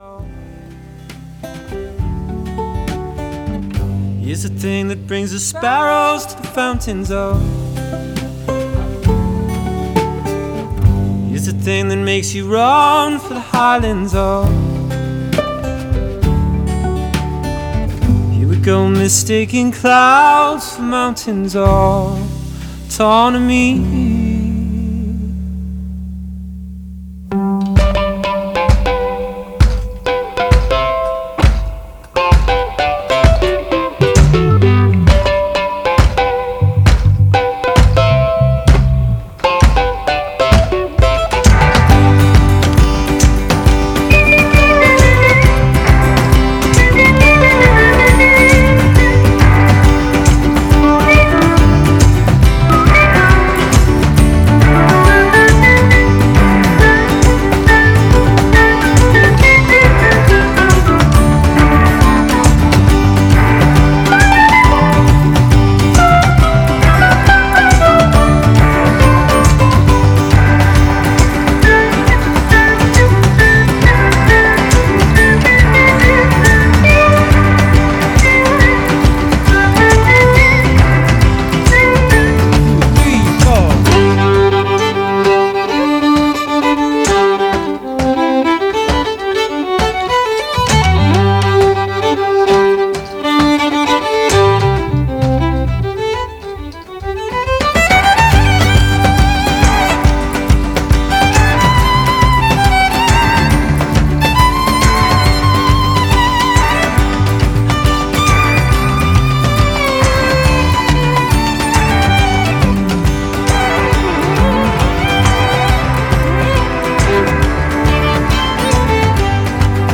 Singer, songwriter, whistler, fiddler